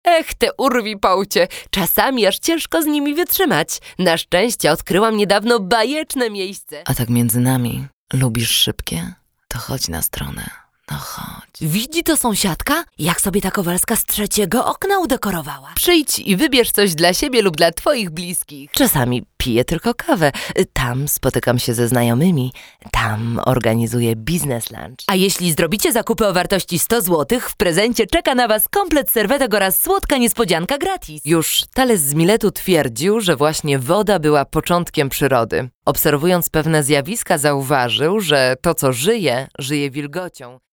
Sprecherin polnisch
Sprechprobe: Industrie (Muttersprache):
female voice over artist polish